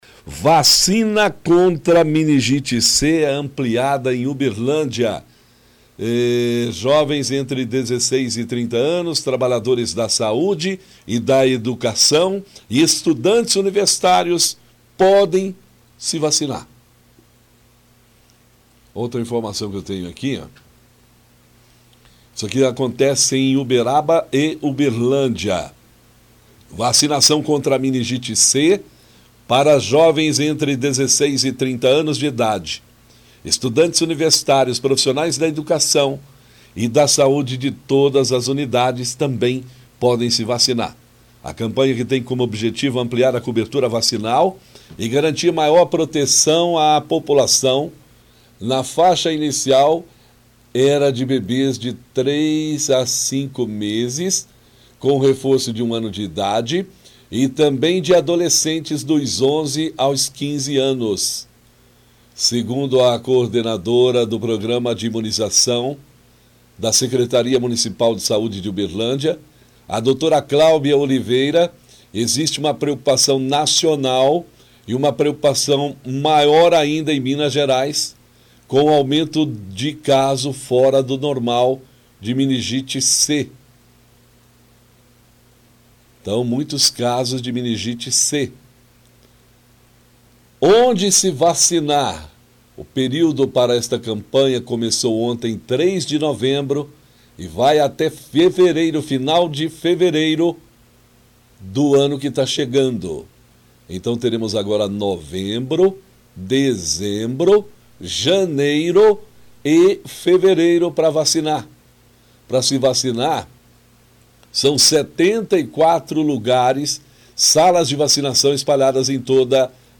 Informa sobre a campanha de vacinação. Leitura de release.